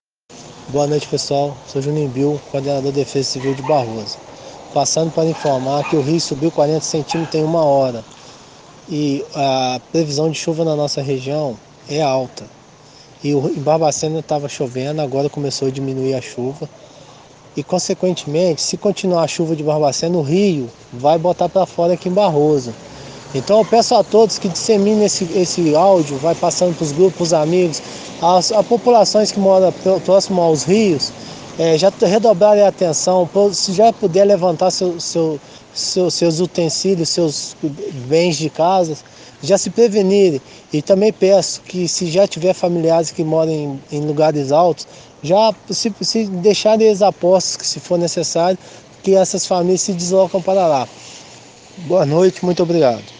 “O Rio subiu 40cm em uma hora, pelo cálculo que fizemos aqui, se a chuva de Barbacena continuar, em 10 horas ele deve subir 4 metros. Se a chuva da madrugada vier, conforme está previsto, o Rio vai colocar muita água para fora antes de amanhecer o dia. Então peço as pessoas que divulguem o máximo possível”, diz o Secretário no áudio que está abaixo.